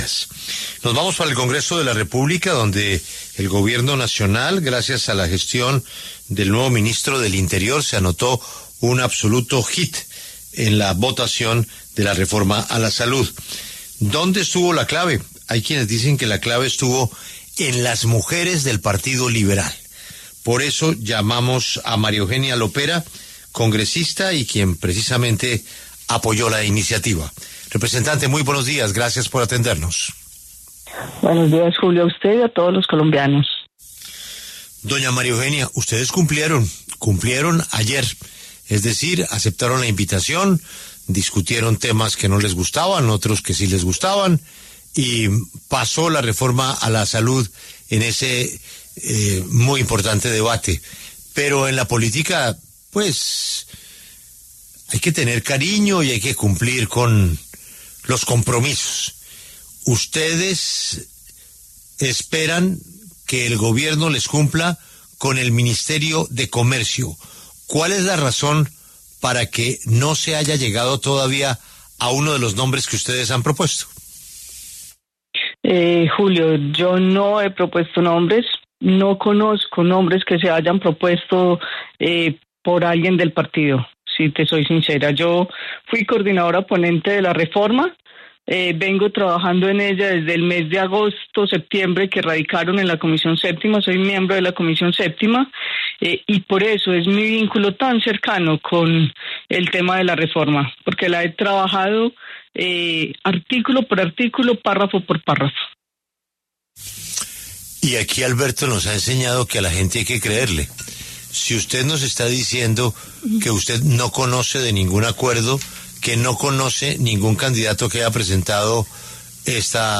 La representante liberal María Eugenia Lopera, quien es coordinadora ponente del proyecto de reforma a la salud, pasó por los micrófonos de La W.